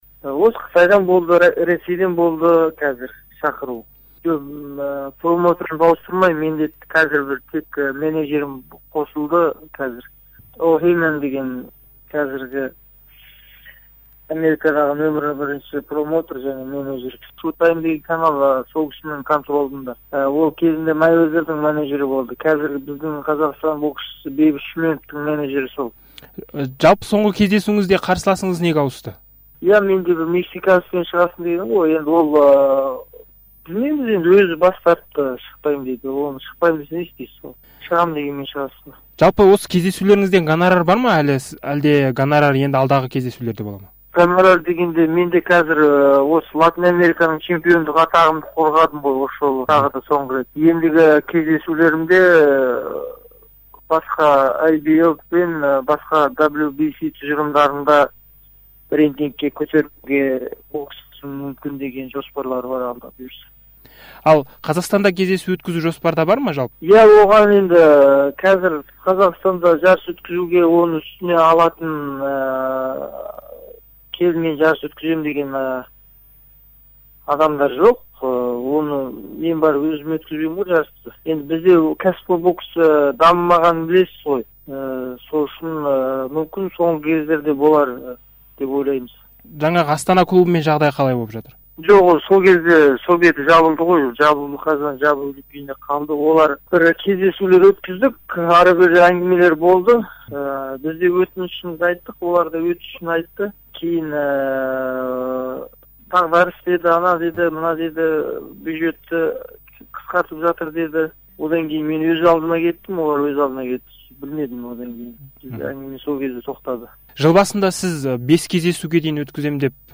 Кәсіпқой рингтегі жиырмасыншы кездесуін де жеңіспен аяқтаған қазақ боксшысы Азаттыққа берген сұхбатында биылғы бірнеше сайысы неге өтпей қалғанын да айтты.
Қанат Исламның сұхбаты